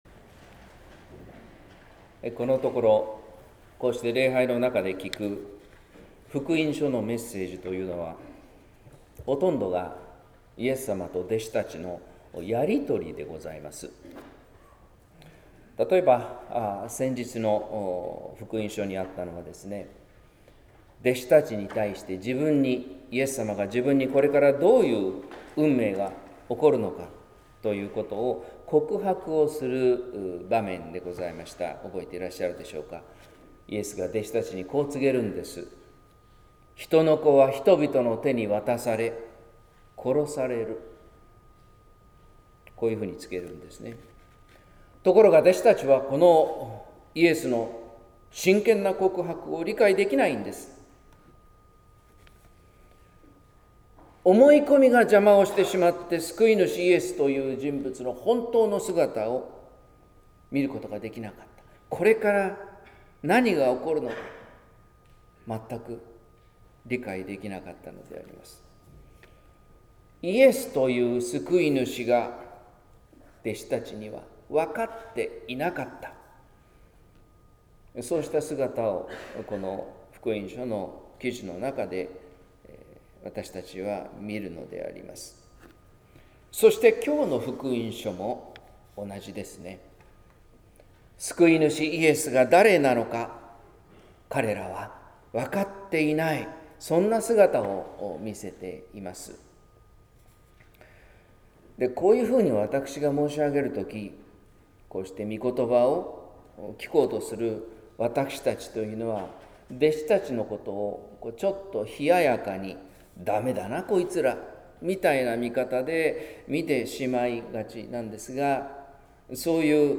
説教「小さなひとりの報い」（音声版） | 日本福音ルーテル市ヶ谷教会